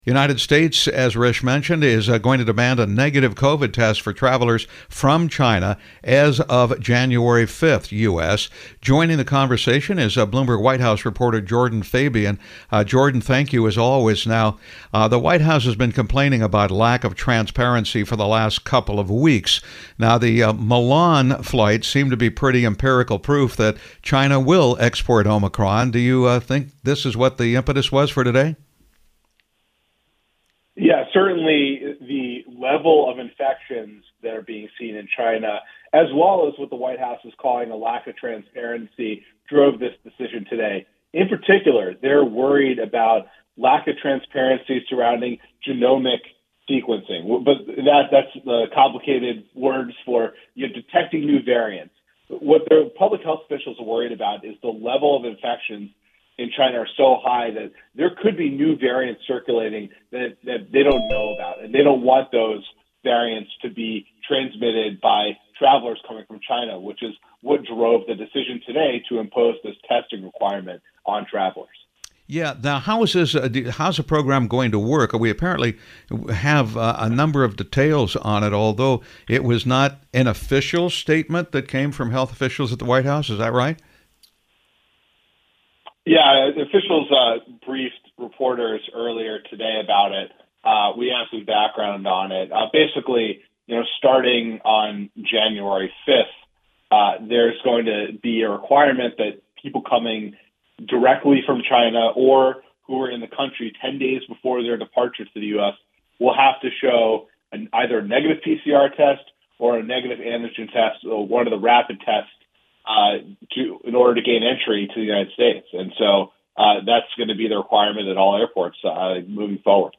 New US COVID Rules for Travelers from China (Radio)